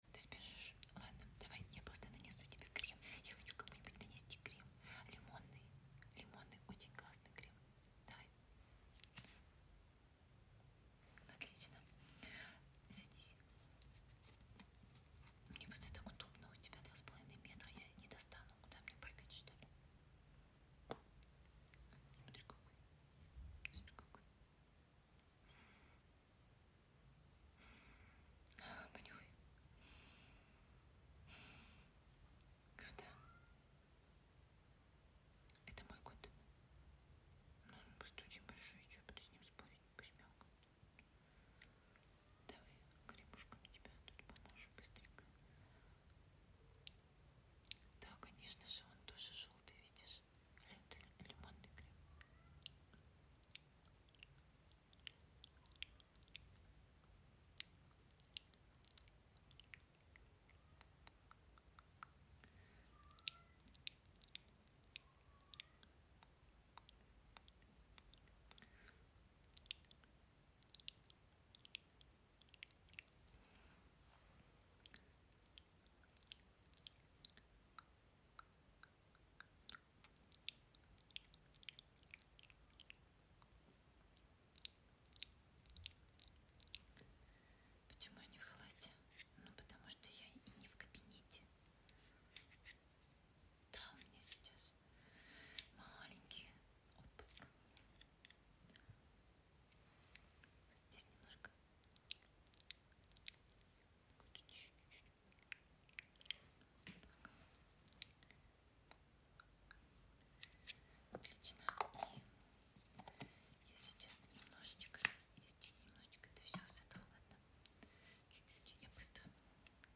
Asmr, But Let Me Put Sound Effects Free Download